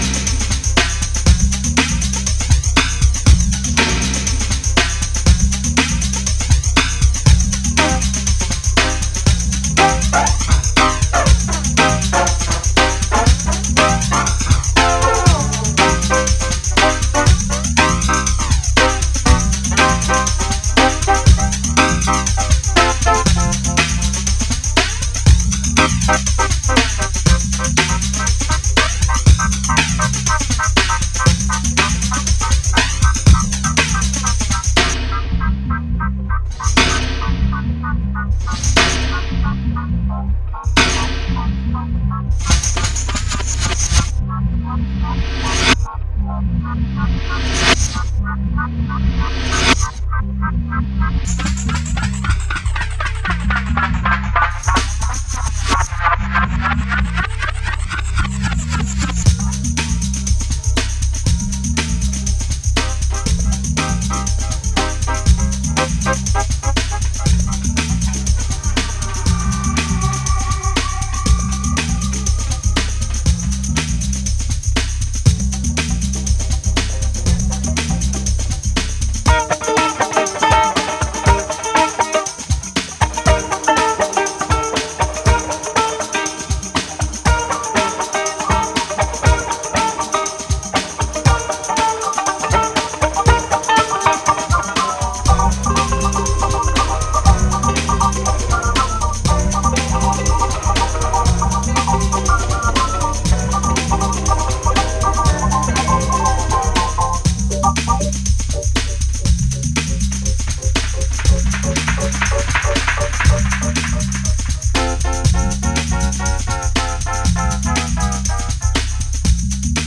ソリッドでダビーなブレイクビーツ〜ダウンテンポ路線に仕上がっていて全曲それぞれナイス。